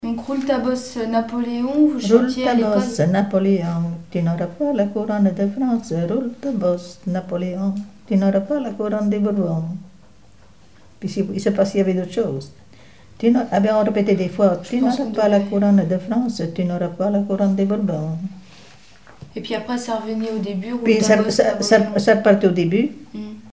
Saint-Jean-de-Monts
Couplets à danser
branle : courante, maraîchine
Pièce musicale inédite